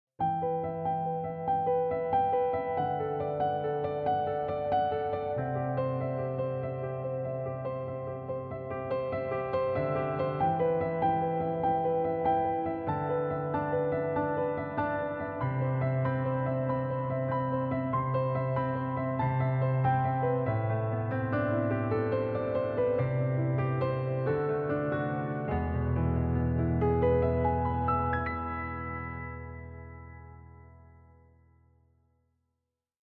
Acoustic Grand piano. Favoritku pas bunyi rilisnya di ending sih.. kalo menurutmu gimana?